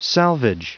Prononciation du mot salvage en anglais (fichier audio)
Prononciation du mot : salvage